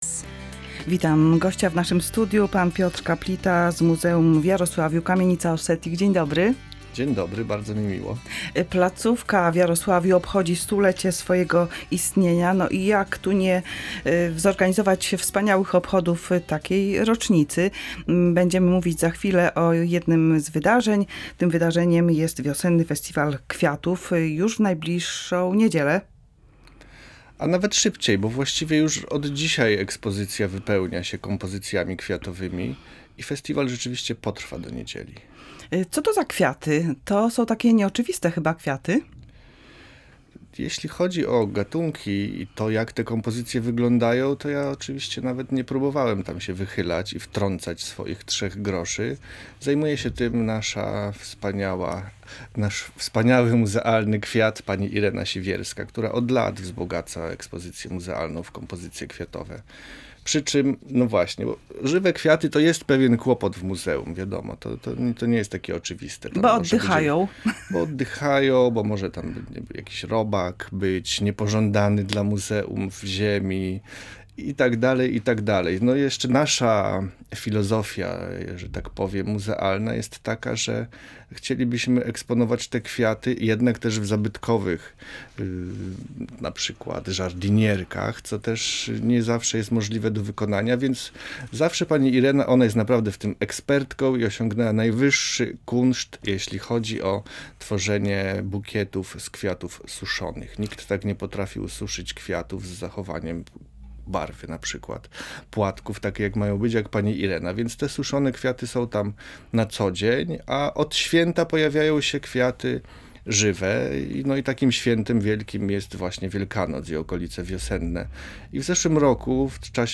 Wiosenny Festiwal Kwiatów • LIVE • Polskie Radio Rzeszów